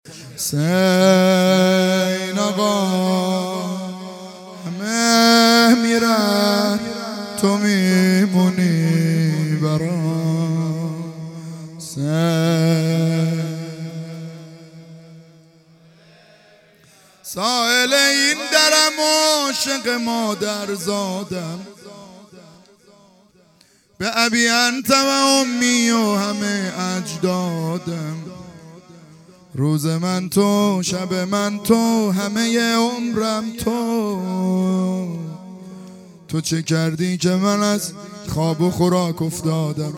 عزاداری دهه اول محرم الحرام 1442